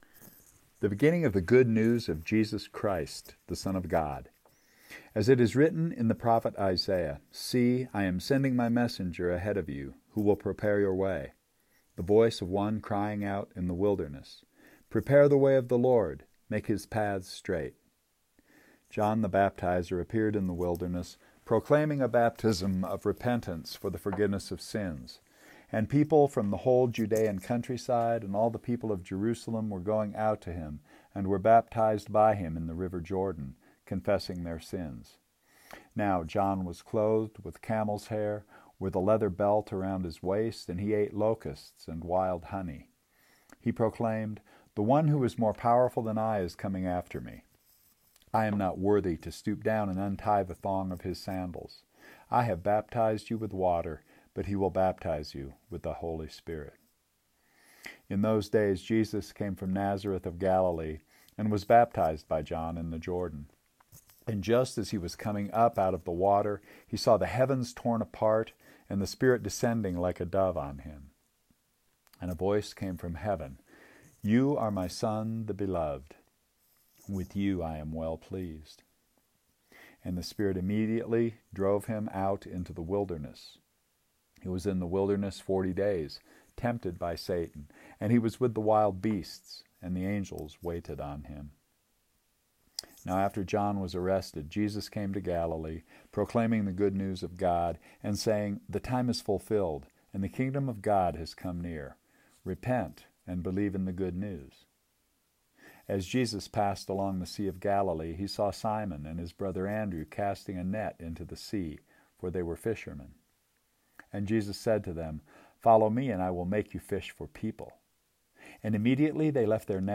It was a story composed to be heard, preferably in one sitting, so that's how we are going to encounter it. Here's a link to a recording of the entire book, read by yours truly.